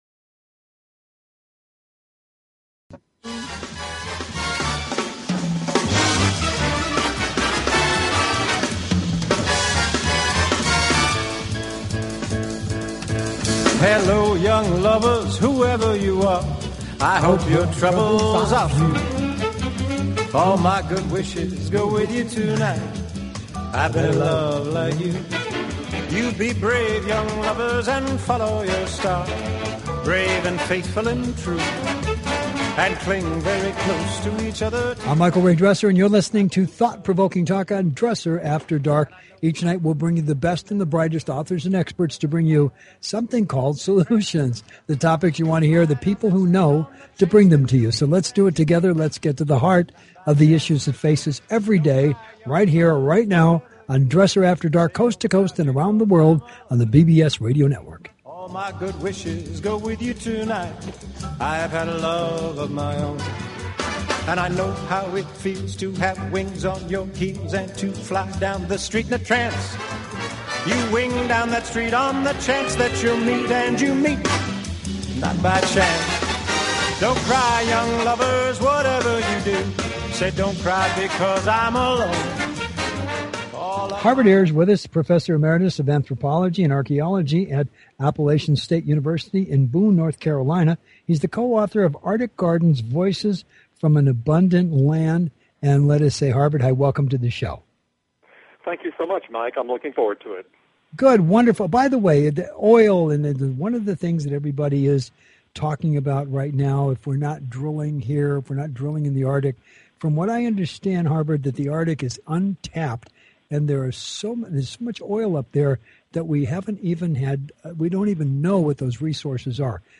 Talk Show Episode